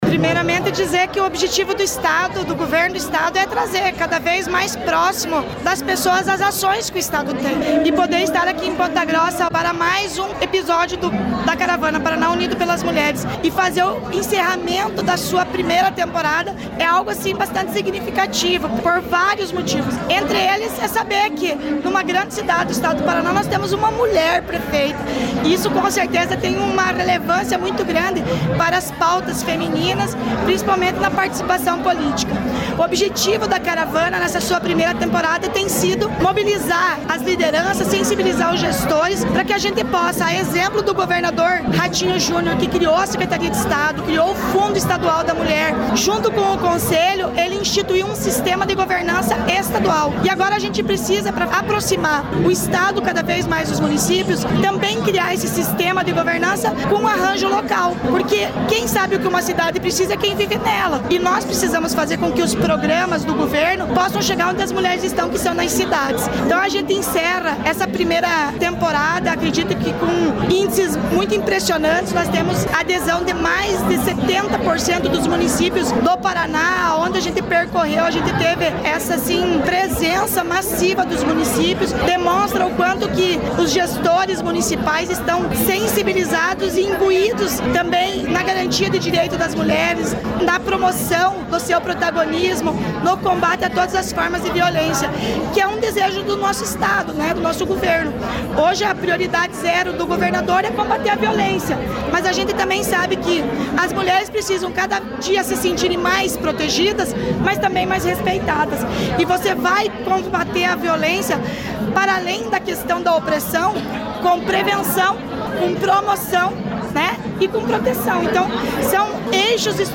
Sonora da secretária da Mulher, Igualdade Racial e Pessoa Idosa, Leandre Dal Ponte, sobre o fim do primeiro ciclo da Caravana Paraná Unido Pelas Mulheres